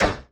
BLLTImpt_Impact Object_06_SFRMS_SCIWPNS.wav